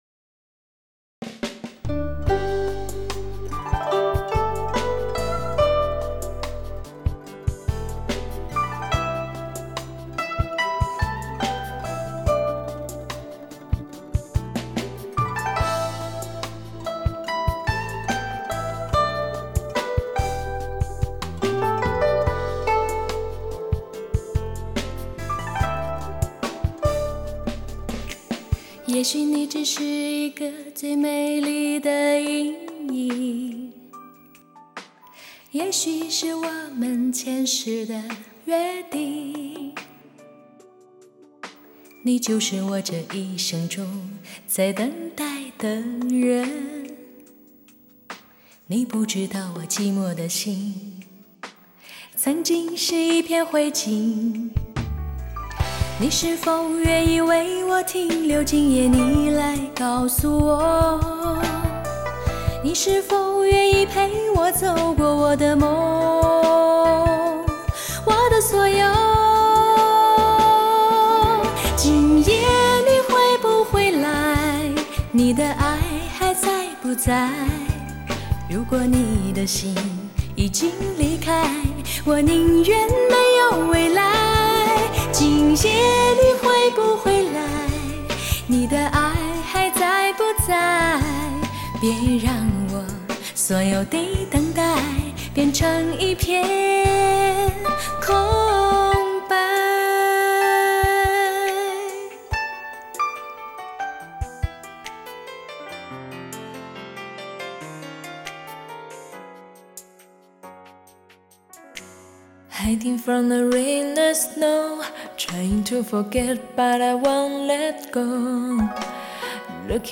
发烧新理念，车载音乐振奋首选，百变动感节奏+
器创造超乎想象完美环绕声震撼体验。